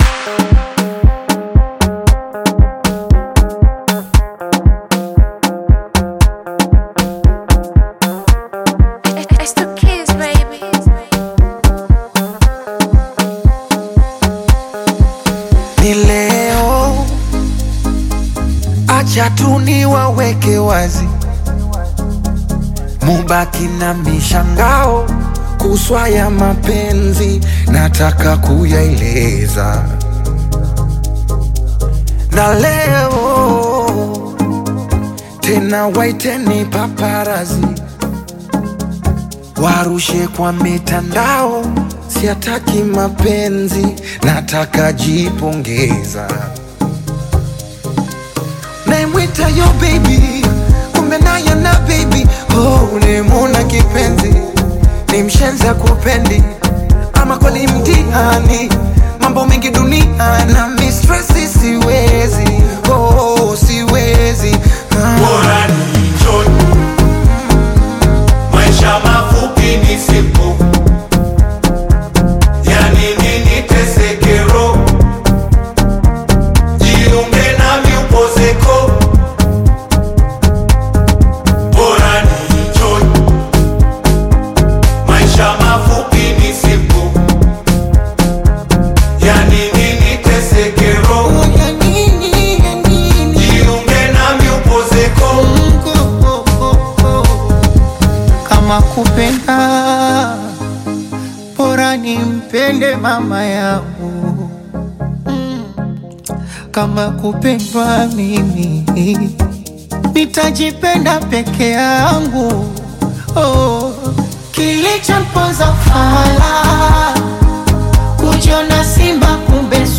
a renowned Tanzanian Bongo Flava recording artist, R&B king